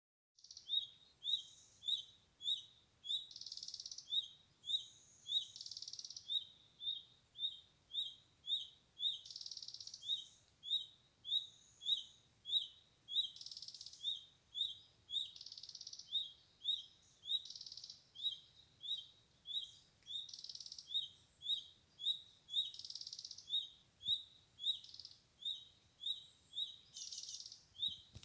Birds -> Flycatchers ->
Red-breasted Flycatcher, Ficedula parva
StatusAdult carrying a faecal sac or food for young